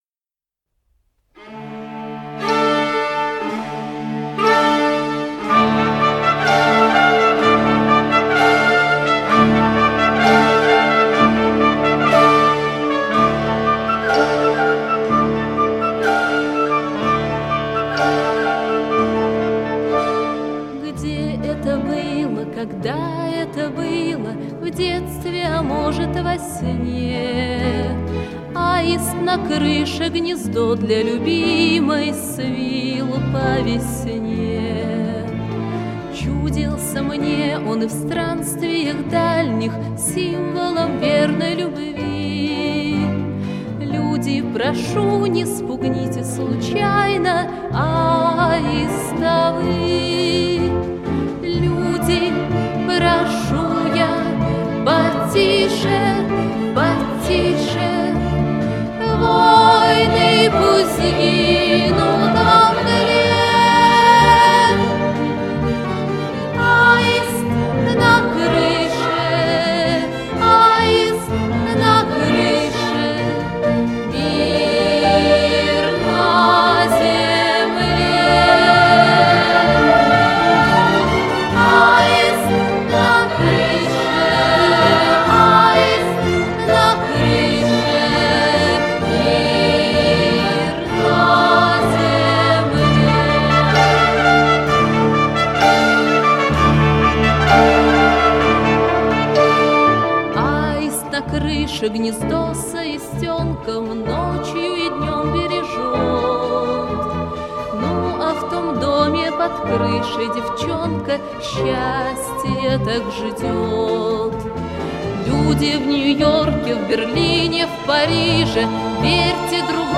Хор песня 1985 года